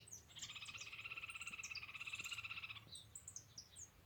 Suiriri Flycatcher (Suiriri suiriri)
Family: Tyrannidae
Country: Argentina
Province / Department: Entre Ríos
Condition: Wild
Certainty: Observed, Recorded vocal